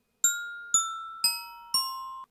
Gliding_hummingbird.ogg